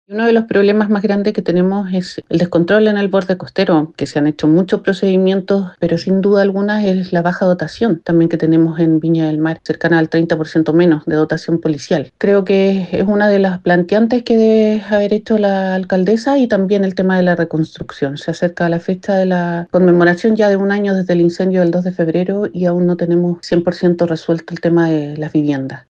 En concreto, fue la concejala del Frente Amplio, Nancy Díaz, quien destacó que esta instancia aporta para que la alcaldesa pueda expresar las principales problemáticas que actualmente tiene la comuna, como es el caso del control en el borde costero y la reconstrucción en las zonas siniestradas por el megaincendio.